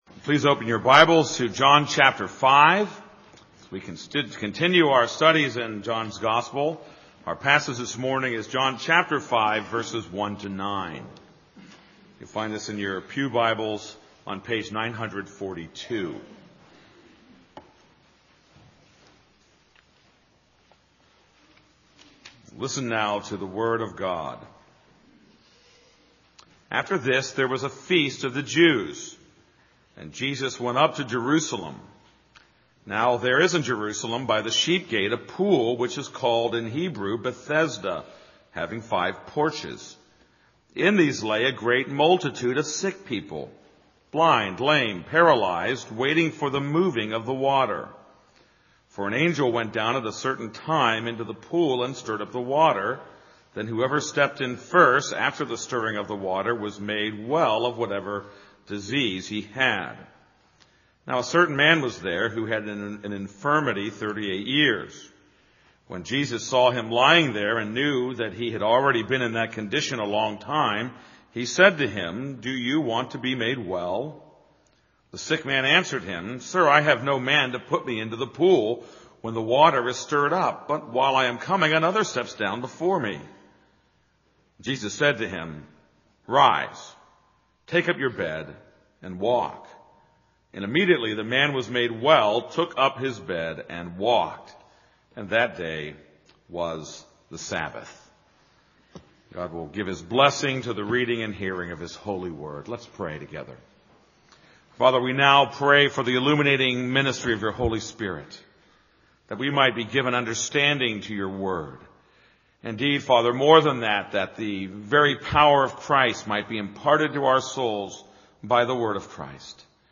This is a sermon on John 5:1-9.